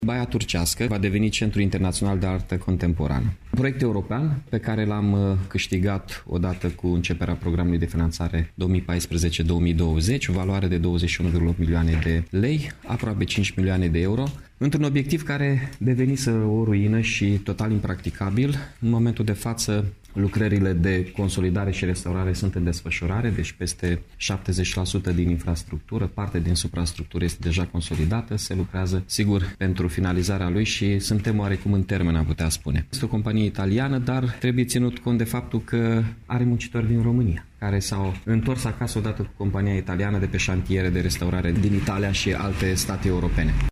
Primarul Mihai Chirica: